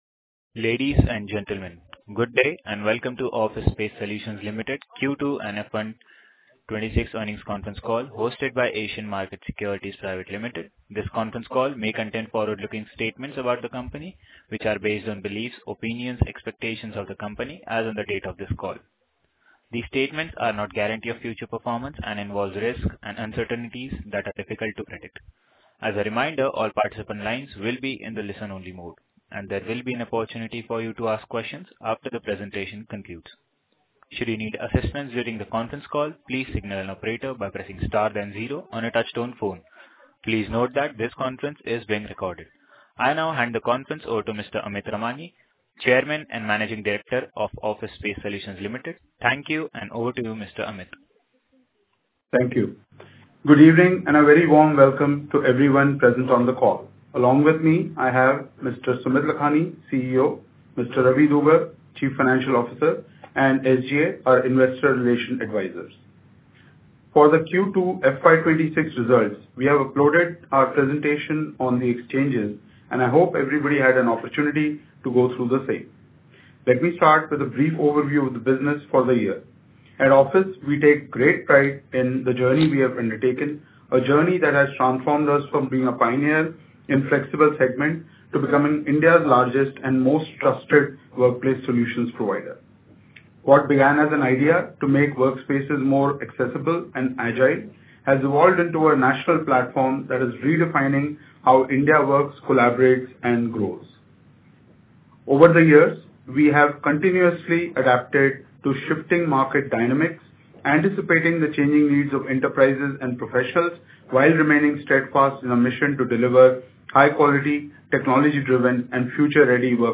Recording Earning Call 12.02.2025